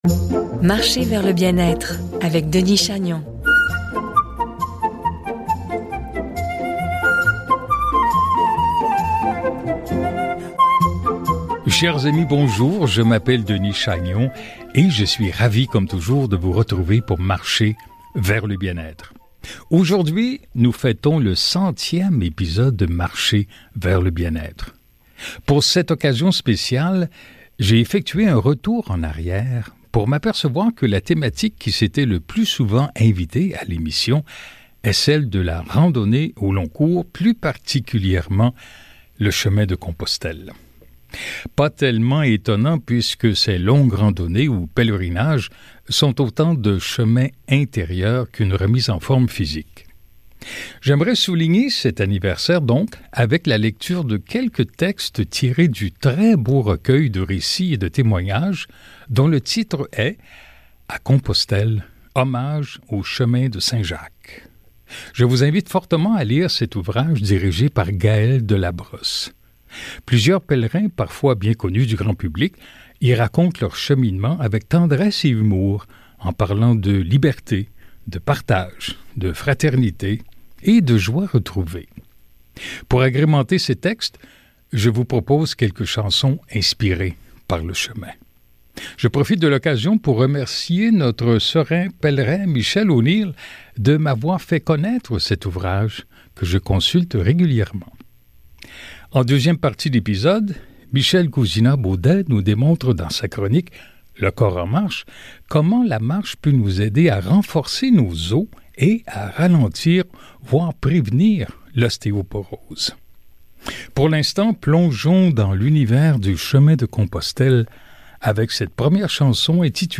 J’aimerais souligner cet anniversaire avec la lecture de quelques textes tirés du très beau recueil de récits et de témoignages intitulé À Compostelle – Hommages au chemin de Saint-Jacques.
Pour agrémenter ces textes, je vous propose quelques chansons inspirées par le chemin.